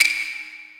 soft-hitclap.ogg